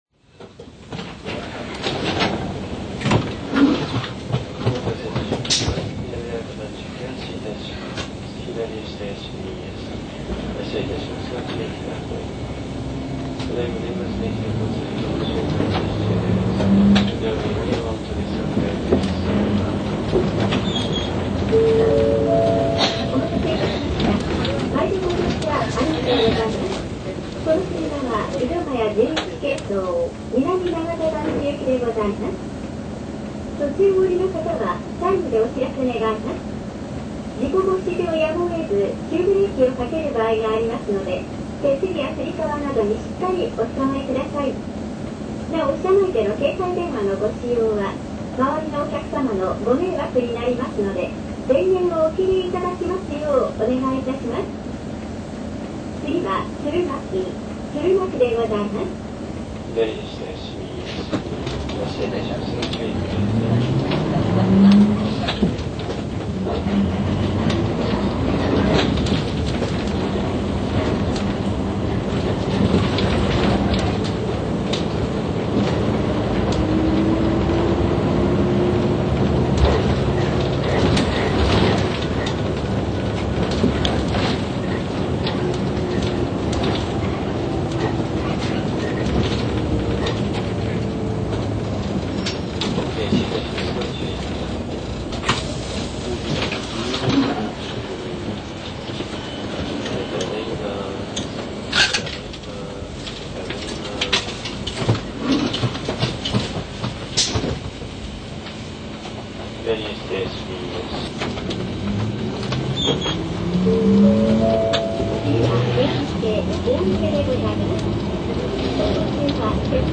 神奈中といえばコレとする程スタンダードな音です。
聴かれない独特の寂しげな響きがあるのがＰ−代までの６Ｄ２２エンジンの特徴といえます。
ココで紹介する走行音は、Ｐ−代は勿論のこと、Ｕ−代でも珍しい戸閉めＯＫサインの大きい車輌です。
井１１系統　井土ヶ谷下町〜鶴巻・上六ッ川〜南永田入口